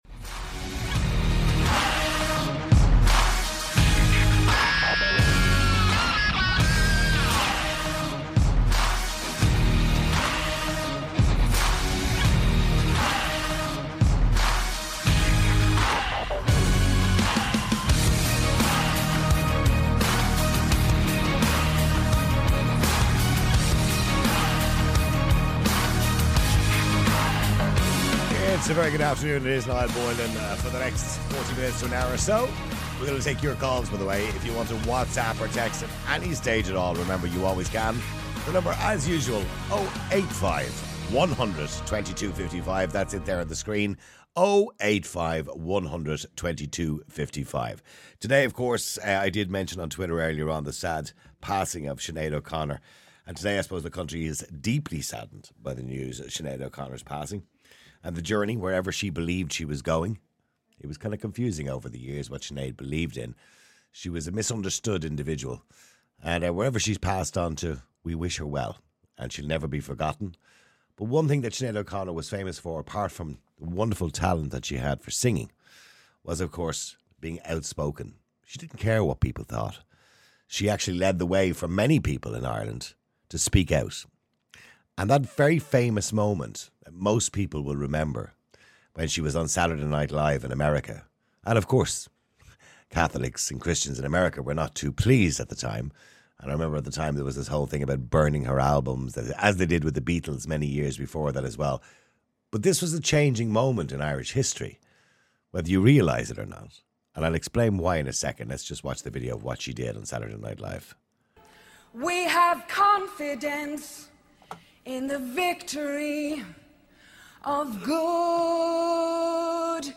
On the other hand, some practicing Catholics call in to passionately defend their faith and beliefs.